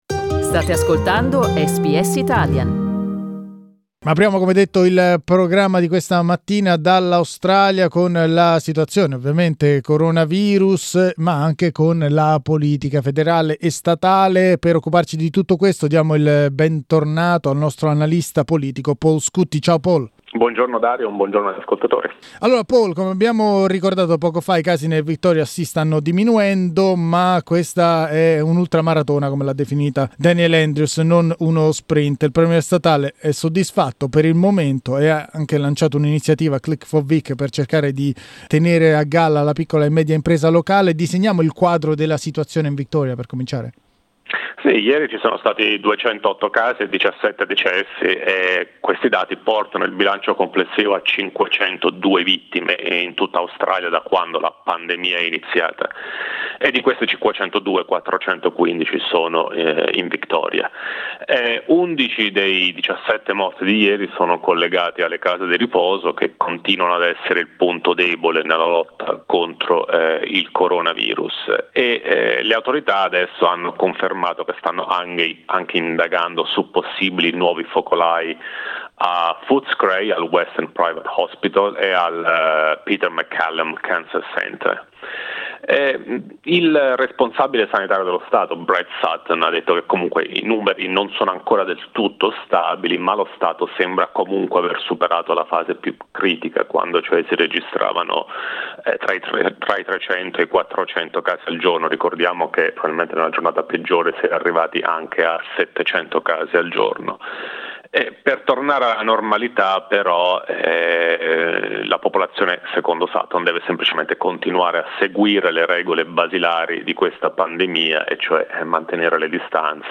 Political commentator